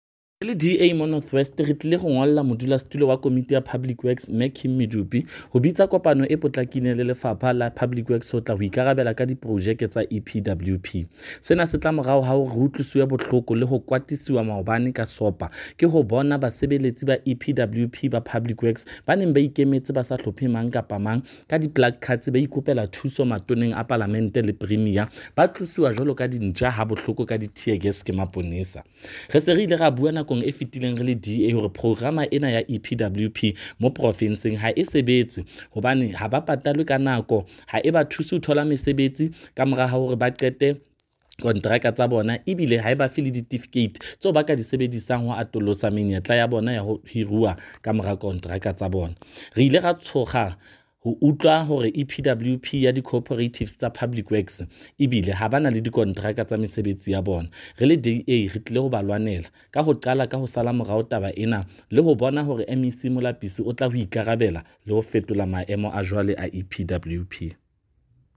Note to Editors: Find attached soundbites in
Sesotho by DA North West Spokesperson on Public Works and Roads, Freddy Sonakile.